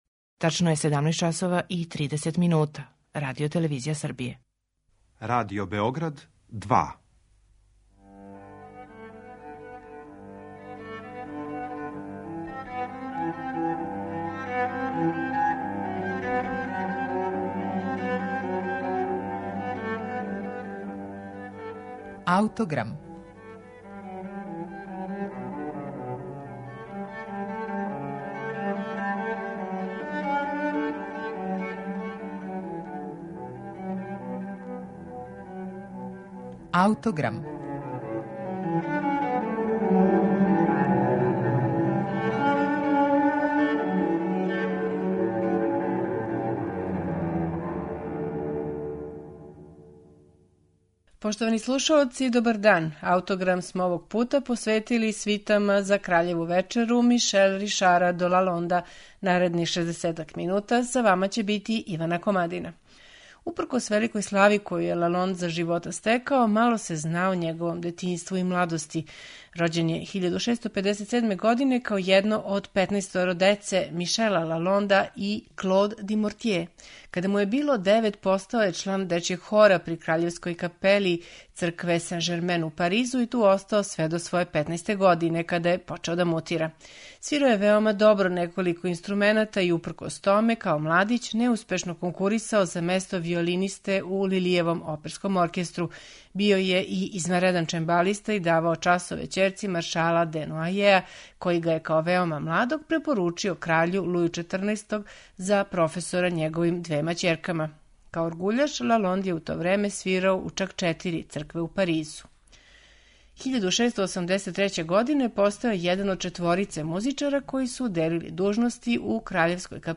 Музика која је извођена у тим приликама најављивана је фанфарама, а након њих је следио низ ведрих плесова и меланхоличних инструменталних арија.